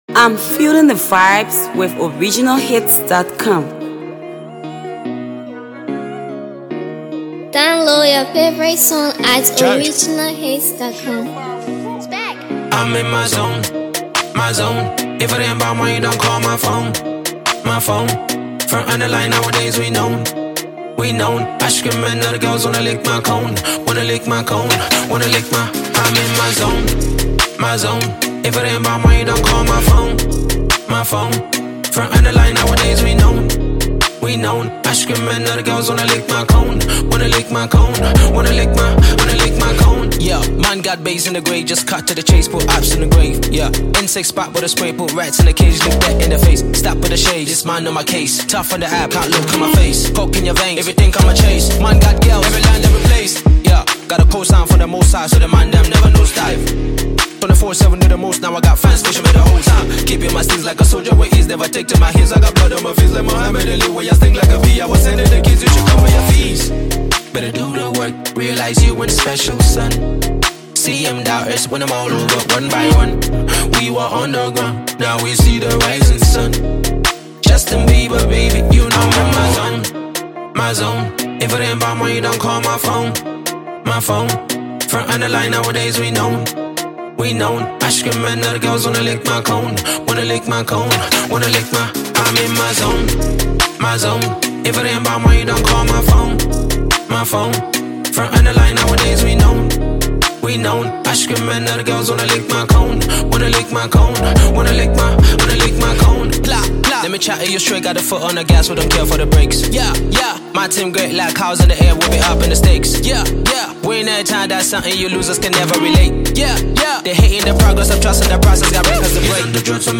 Liberian reality hip-hop rap artist